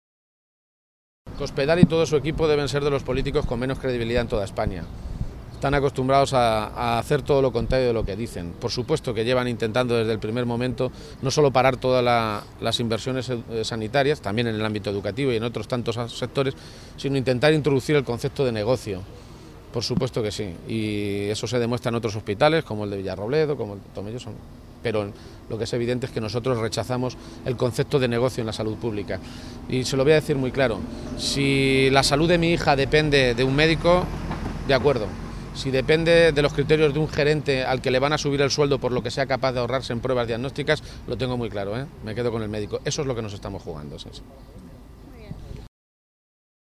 Hacía estas declaraciones García-Page esta mañana, en Toledo, a preguntas de los medios de comunicación y después de que el grupo parlamentario socialista haya anunciado que va a registrar en las Cortes de Castilla-La Mancha una proposición de Ley que tiene como objetivo blindar el sistema público sanitario y que hace imposible privatizarlo.
Cortes de audio de la rueda de prensa